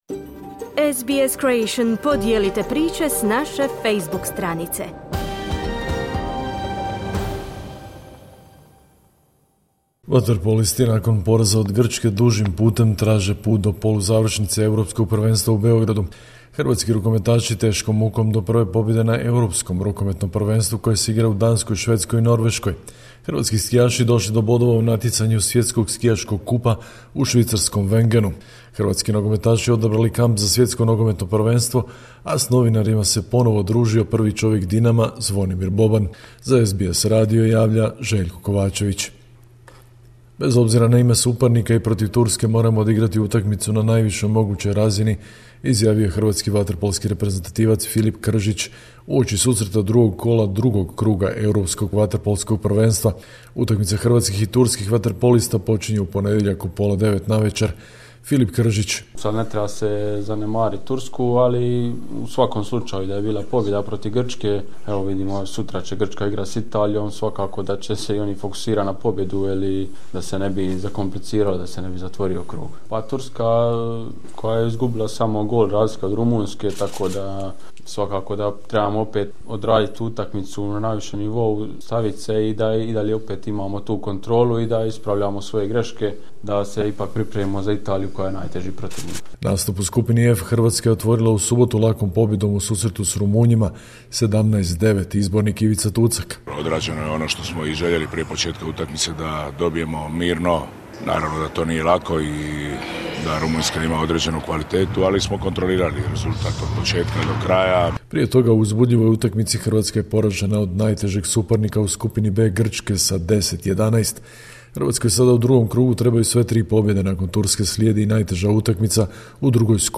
Sportske vijesti iz Hrvatske, 19.1.2026.